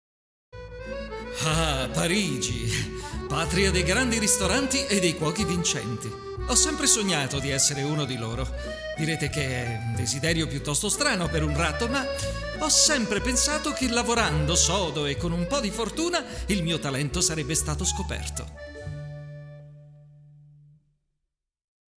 Speaker italiano, attore doppiatore, studio proprio, voice over, voce versatile, off-speaker
Sprechprobe: Werbung (Muttersprache):